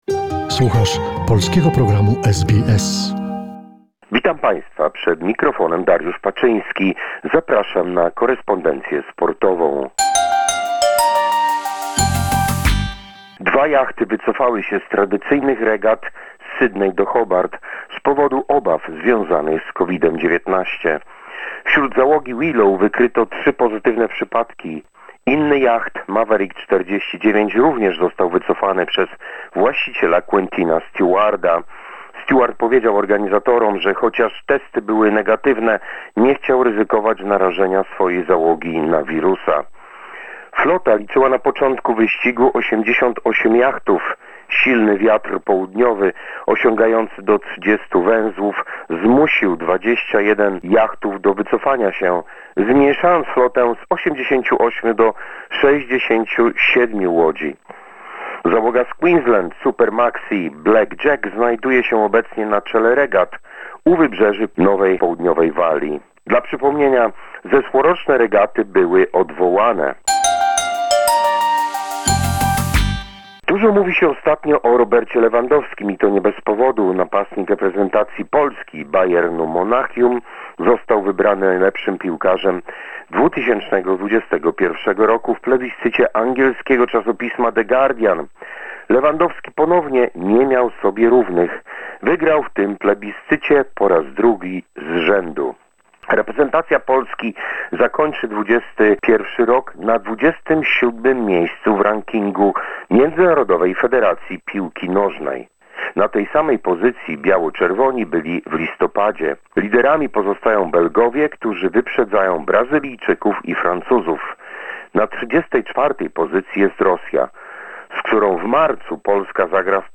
presents Monday sports summary of the week.